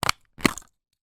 Звуки градусника
Звук: вынимаем стандартный ртутный градусник из пластикового футляра